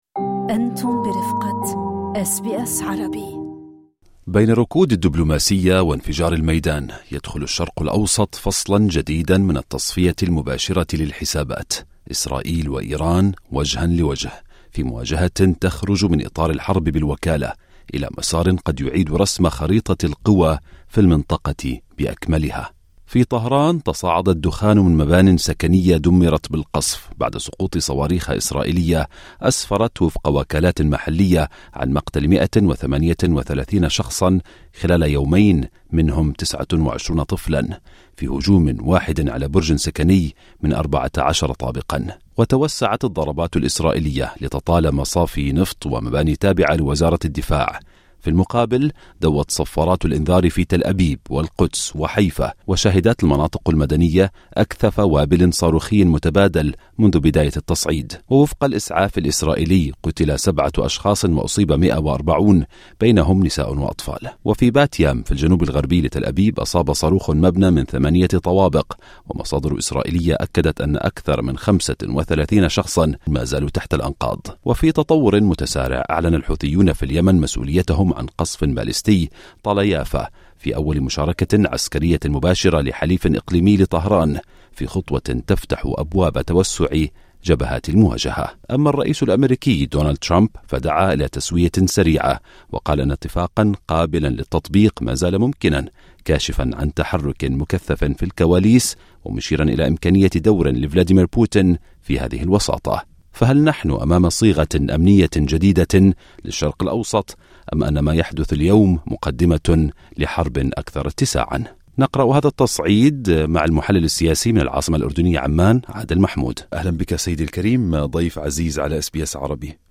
"إيران جديدة": محلّل سياسي يقرأ بين سطور التصعيد غير المسبوق بين طهران وتل أبيب